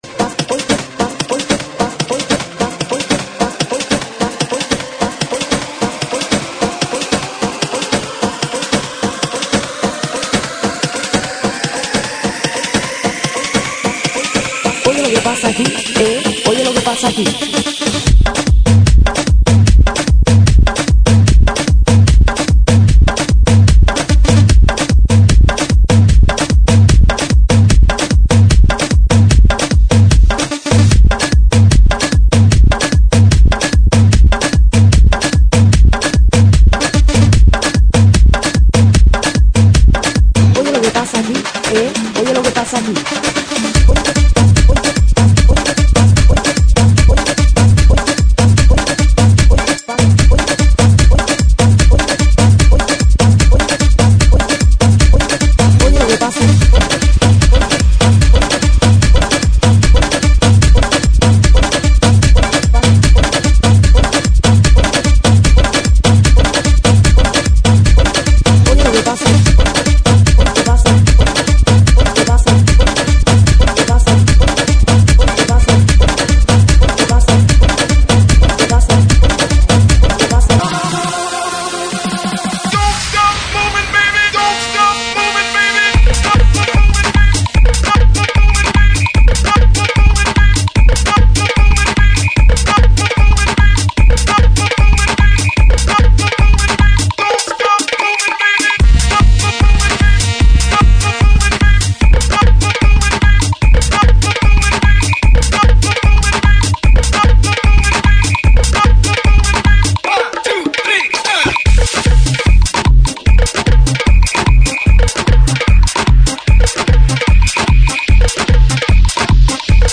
GENERO: ELECTRO – POP